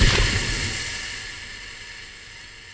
catalytic refinery cycle end